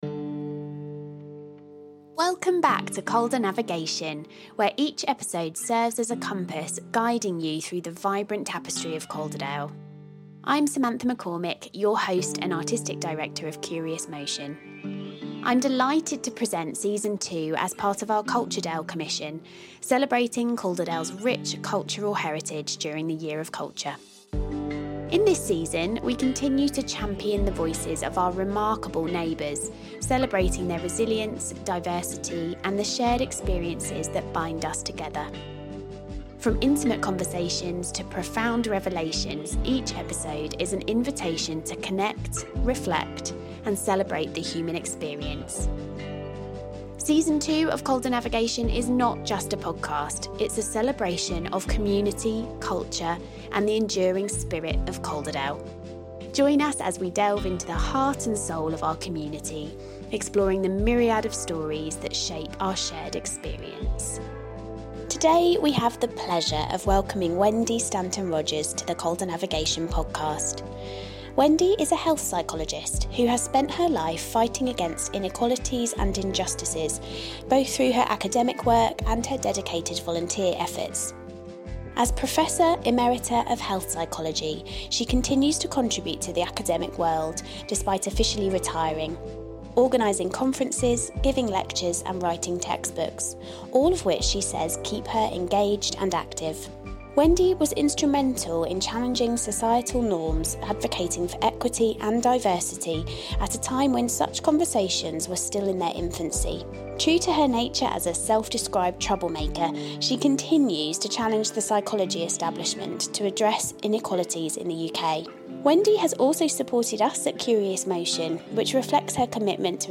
It was such an interesting conversation, and we can't wait for you to hear it.